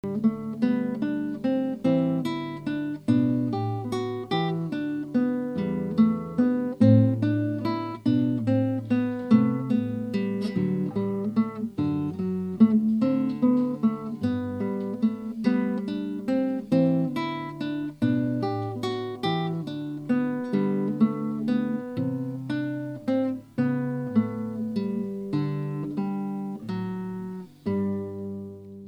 Ashington Folk Club - Spotlight 21 July 2005